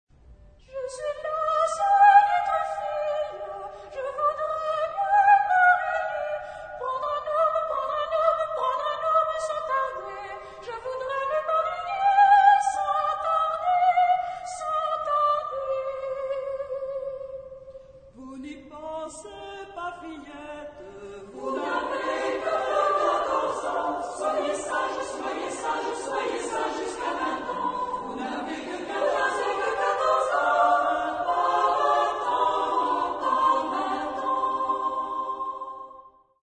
Genre-Style-Forme : Folklore ; Profane ; Chanson
Type de choeur : SSA  (3 voix égale(s) d'enfants OU égales de femmes )
Solistes : Soprano (1)  (1 soliste(s))
Tonalité : mi bémol majeur